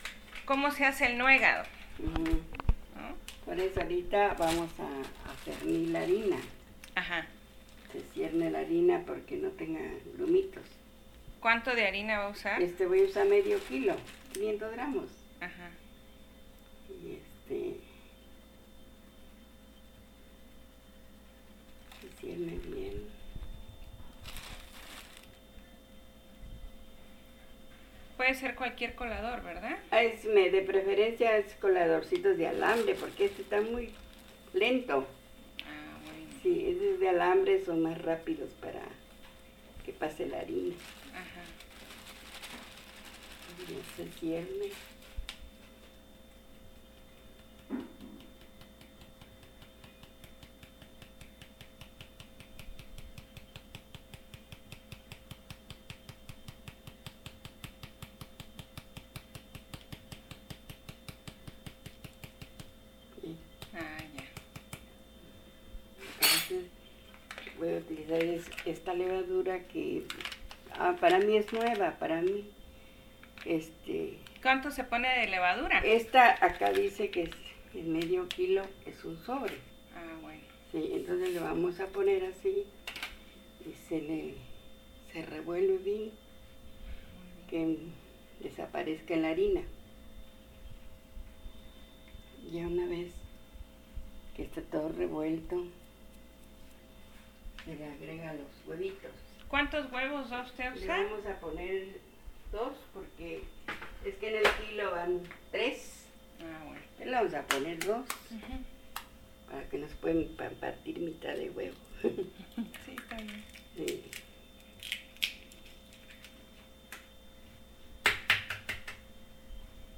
El sonido del agua en ebullición, el de la tetera, del sartén con la cuchara, del aceite caliente, el cuchillo cortando las frutas o verduras, la licuadora, el de las manos al amasar o mezclar; sonidos que se enriquecen con las conversaciones, por lo que el silencio es el eterno ausente.
archivosonoro-elaboracion-nuegado.mp3